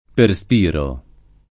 perspiro [ per S p & ro ]